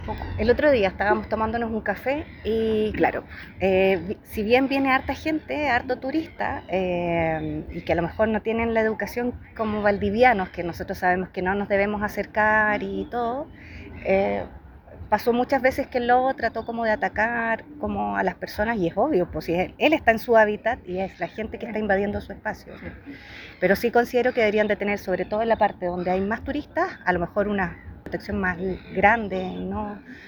Además, dos vecinas del sector plantearon la necesidad de reforzar las medidas de protección para evitar que los lobos marinos suban a la costanera.
cuna-2-valdivianas.mp3